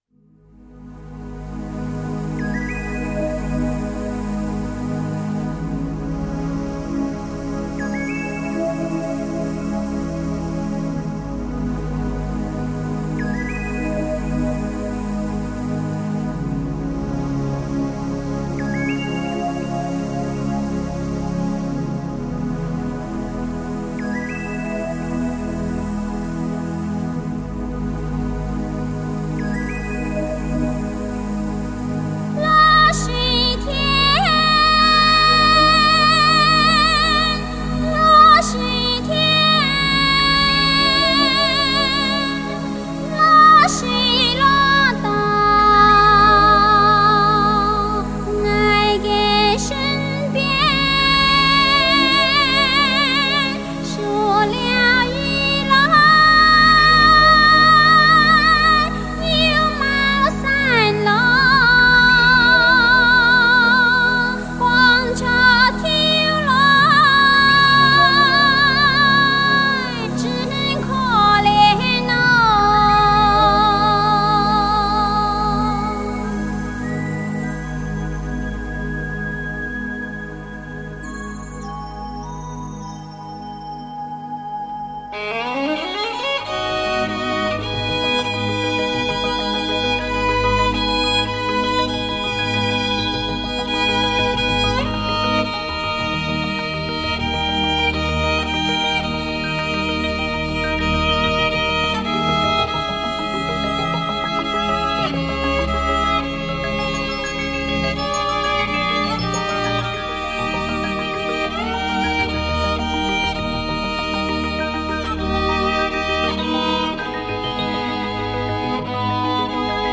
全面刷新发烧天碟制作理念，中国第一张原生态山歌与交响乐完美结合的顶级发烧天碟，
从丰富的音响织体中，我们可以感受到更为廓大的音乐空间。